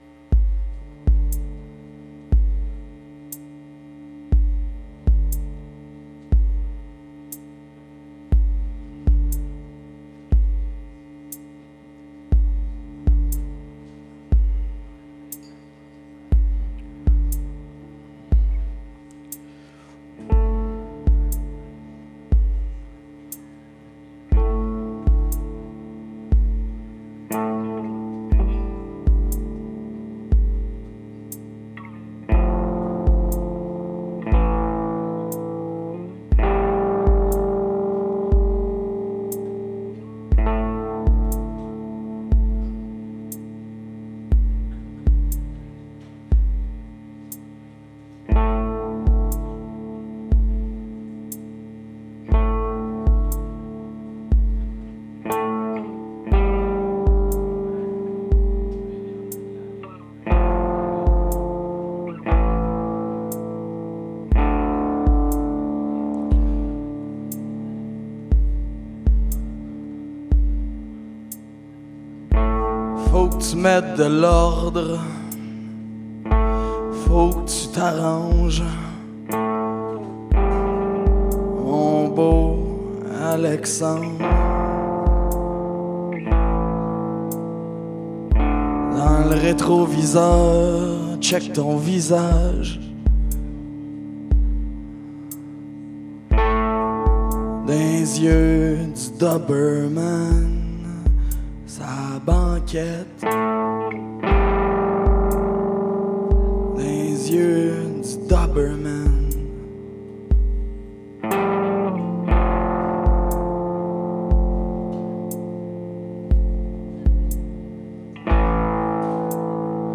C’est aussi de redoutables improvisateurs.
à la voix